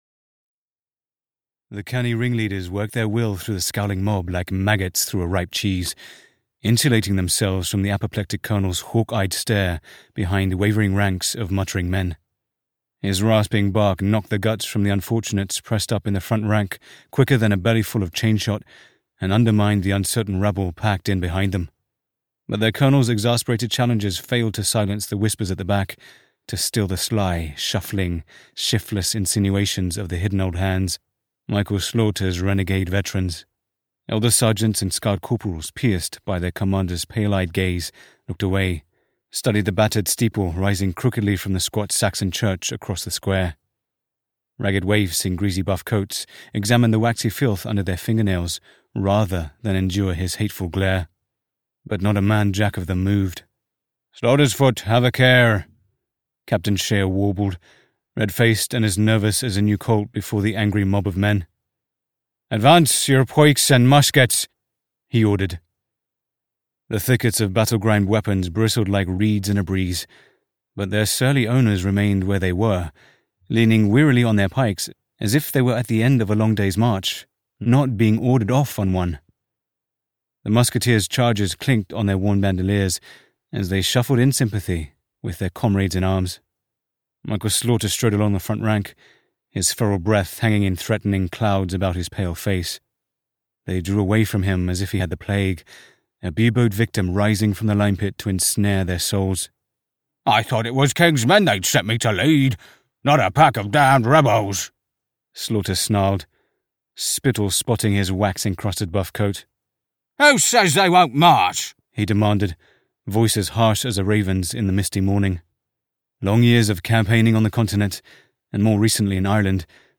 King's Men Crow (EN) audiokniha
Ukázka z knihy